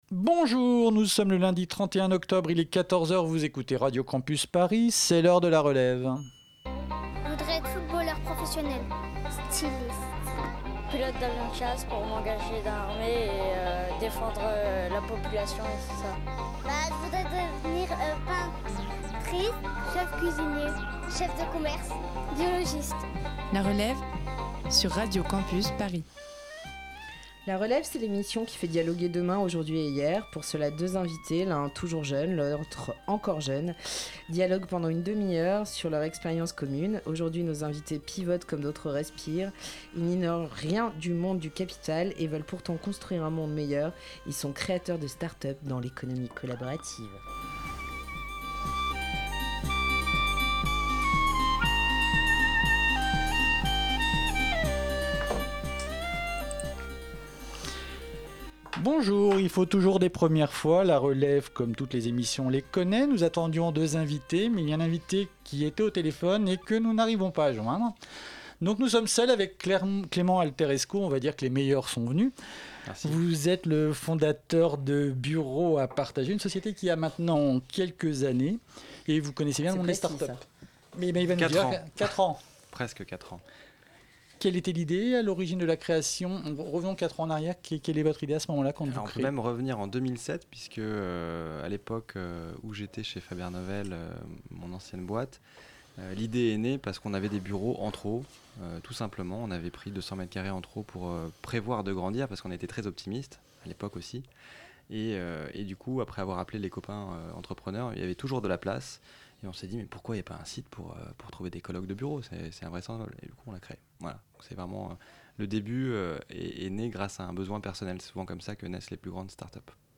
Et oui, cette semaine, les aléas de l'enregistrement en condition du direct ont fait que nous n'avons qu'un seul invité.
Entretien